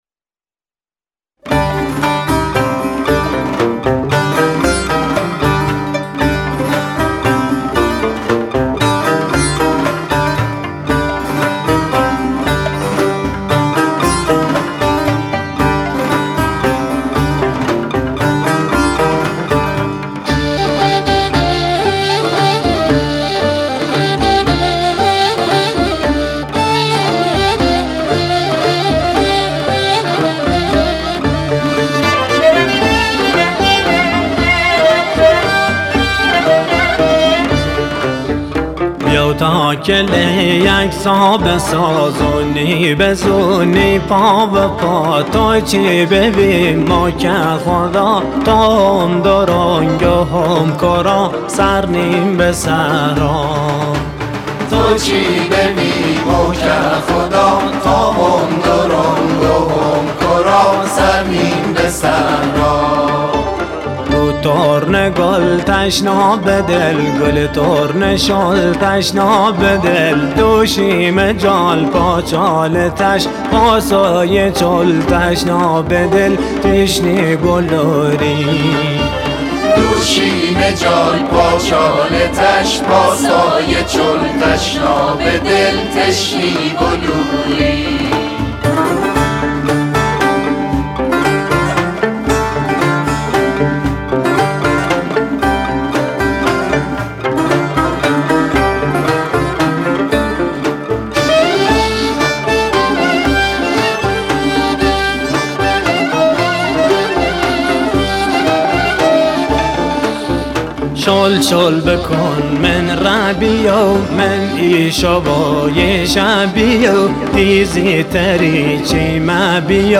Lori music